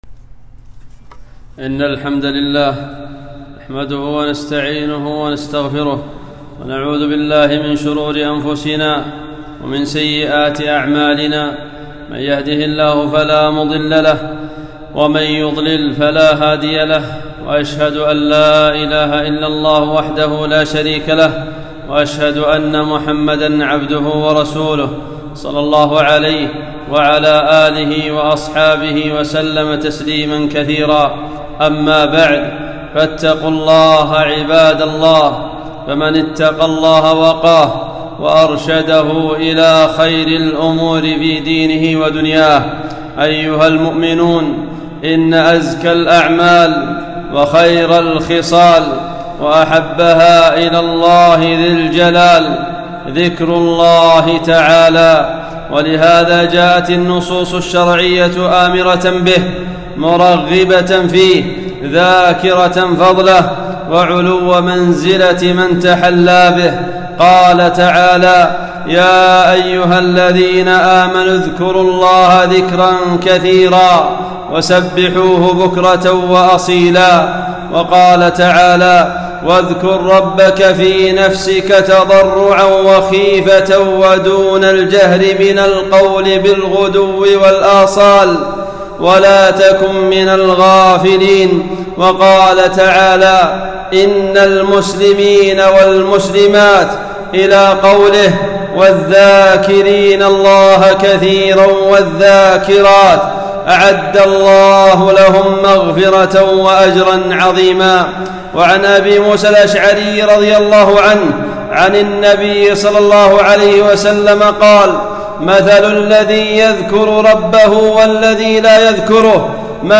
خطبة - ذكر الله تعالى